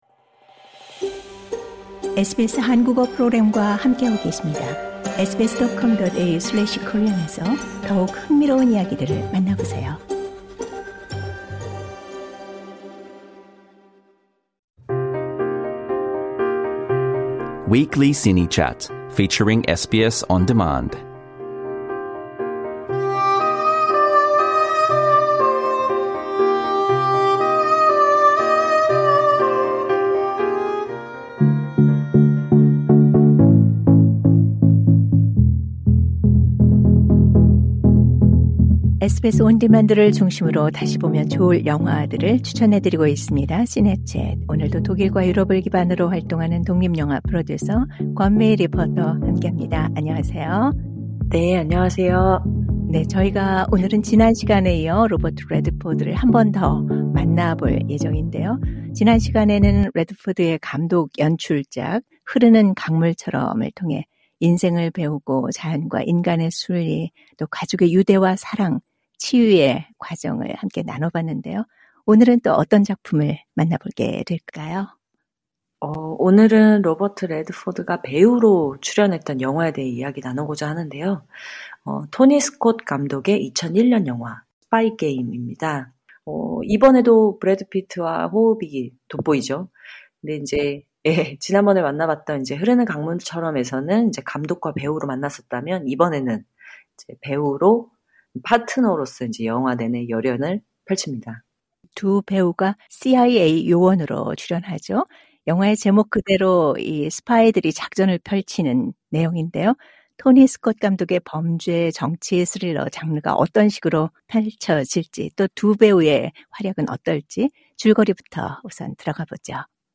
Trailer Audio Clip 미국과 중국의 국제 협력 관계의 소음을 줄이고자 그러니까 이제 이 사건으로 인해서 이제 관계 악화가 우려가 된 이제 CIA 본부에서는 비숍을 그냥 제거할 목적으로 미호를 호출해 그간의 상황과 또 비숍에 대해서 조사를 진행합니다.